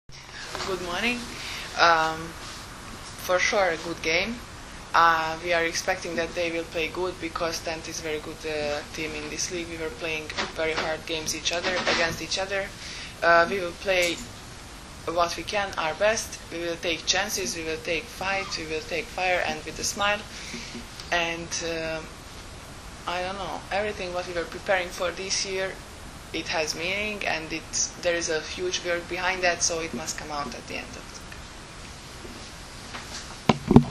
U prostorijama Odbojkaškog saveza Srbije danas je održana konferencija za novinare povodom Finalnog turnira 48. Kupa Srbije u konkurenciji odbojkašica, koji će se u subotu i nedelju odigrati u dvorani “Park” u Staroj Pazovi.